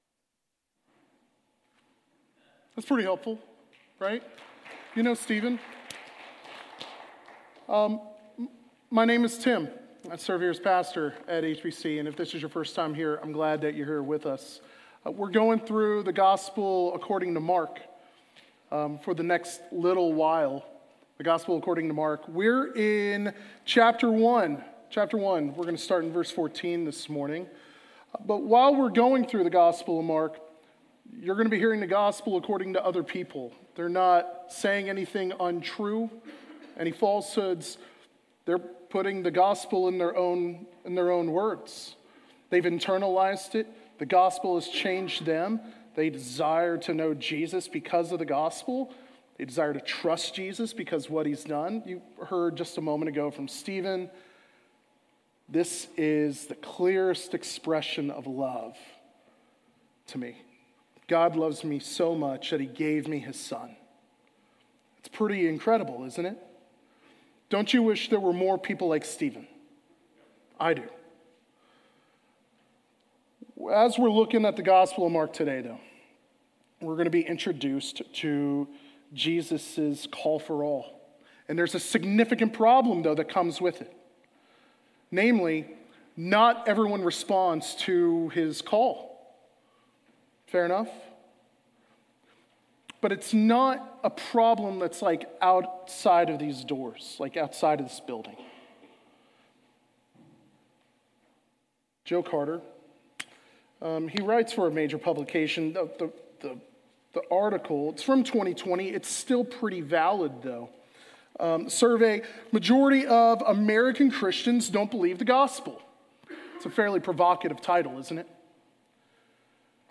Sermons | Hazelwood Baptist Church